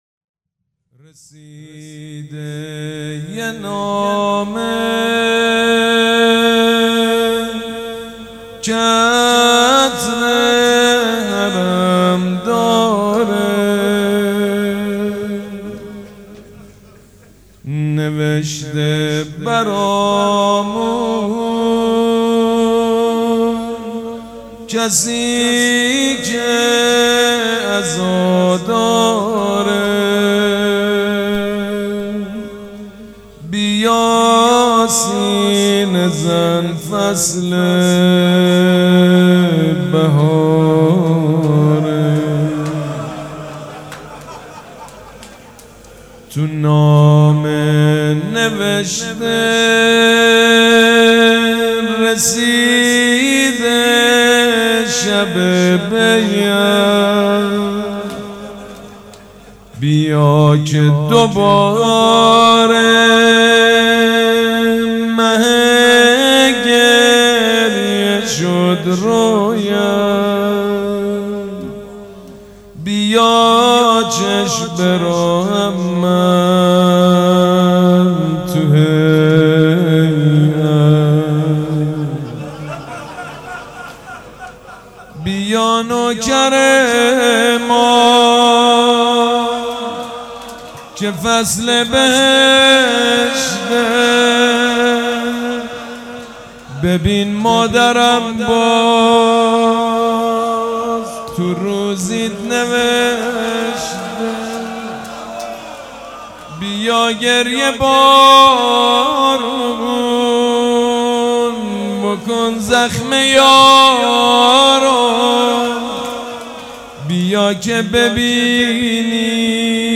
روضه بخش اول
حاج سید مجید بنی فاطمه جمعه 16 شهریور 1397 هیئت ریحانه الحسین سلام الله علیها
سبک اثــر روضه
روضه اول.mp3